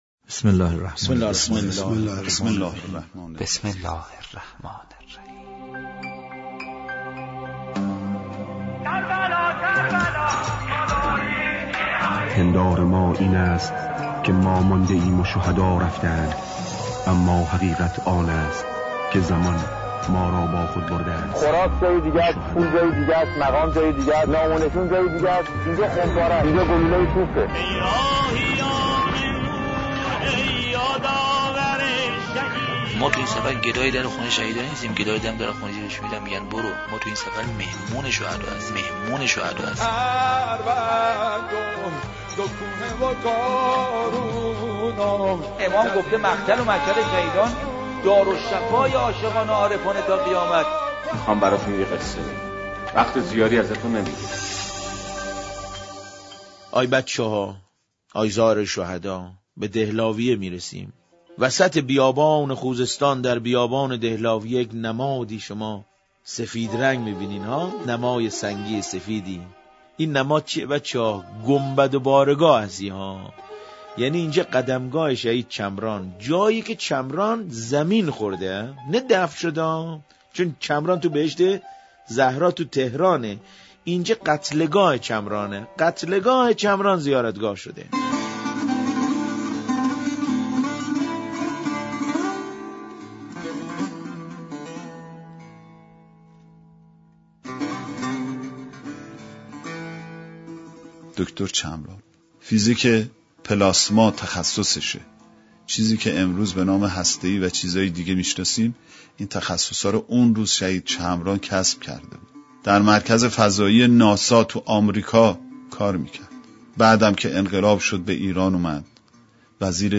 صوت روایتگری از شهید چمران و یادمان دهلاویه با صدای حاج حسین یکتا و صدای دکتر چمران .